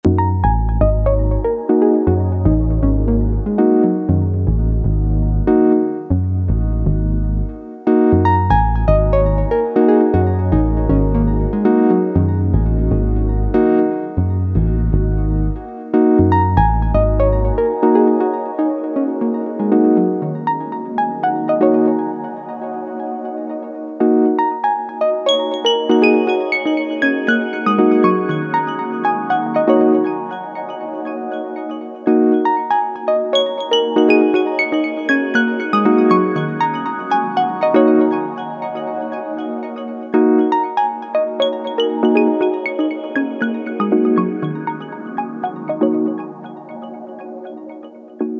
Soundwise it sounds transparent and clean - the filters and effects sound good too!
EDIT: quick reverb n delay test with the P12 (bass is also P12 but on its own channel w/o fx - this is partly why I got the bluebox in the first place!):